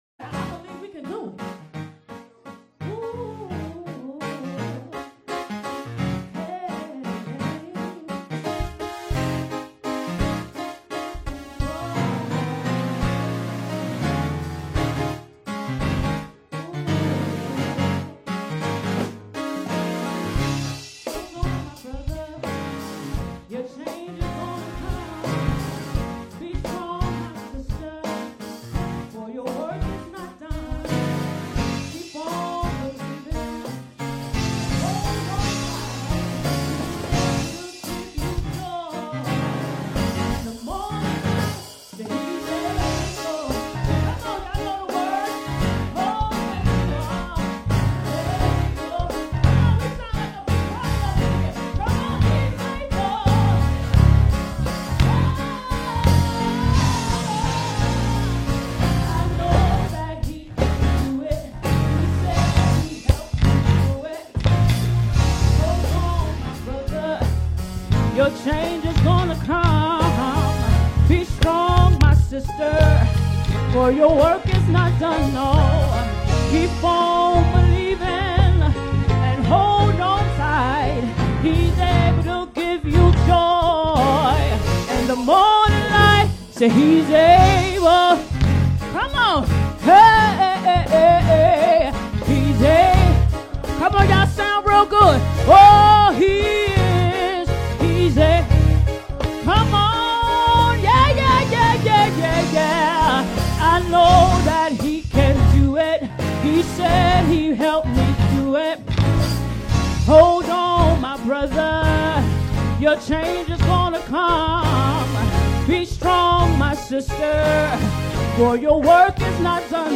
Sermons | Greater Cornerstone Baptist Church